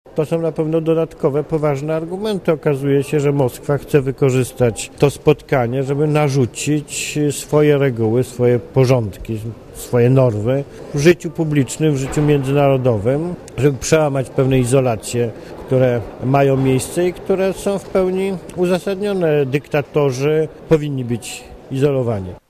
Mówi Jarosław Kaczyński